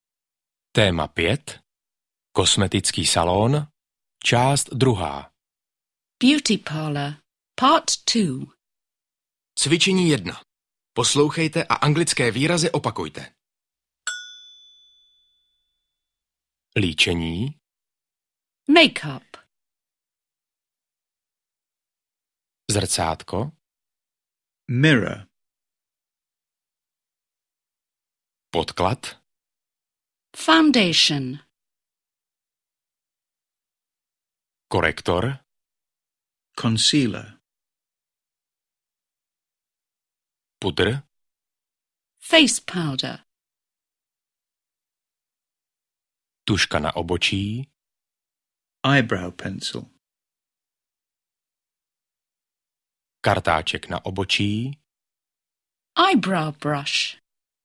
Audiokniha Anglicky bez učebnice - Cestování a služby obsahuje nahrávky v anglickém jazyce s českým komentářem, různorodá témata a cvičení včetně opakování slovíček a správné výslovnosti.
Čte: Různí interpreti